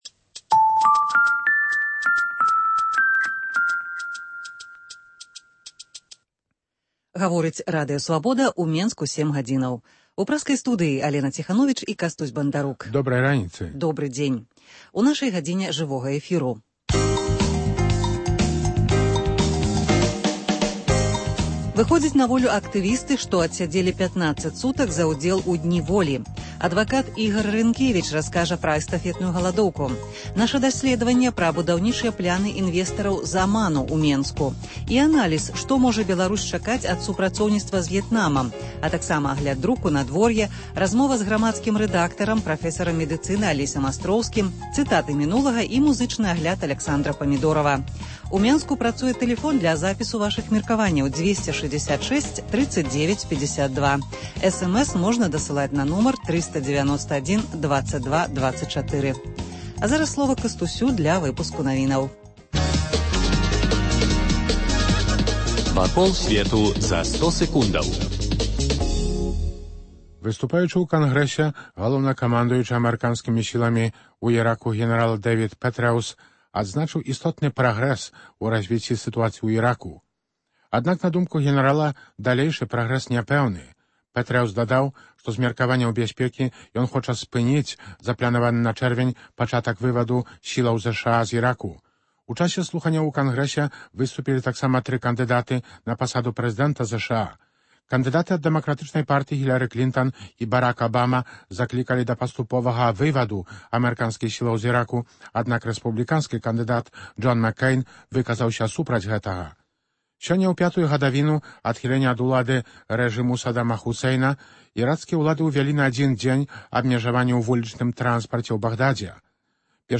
Ранішні жывы эфір